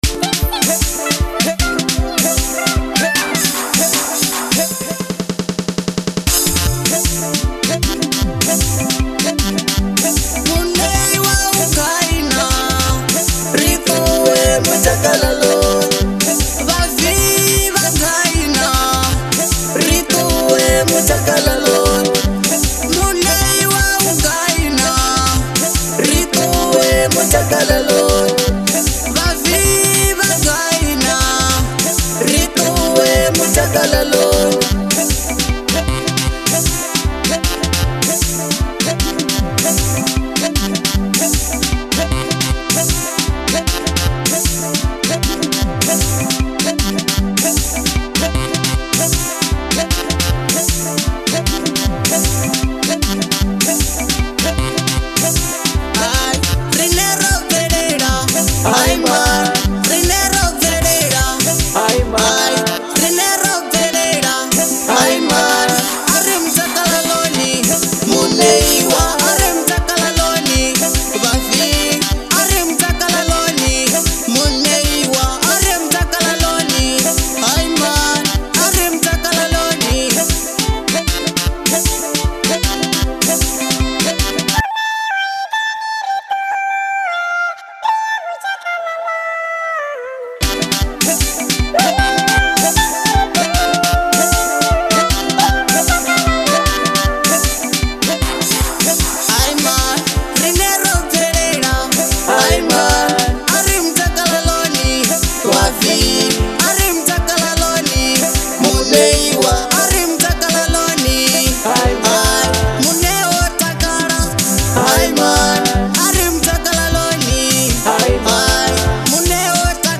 Genre : Manyalo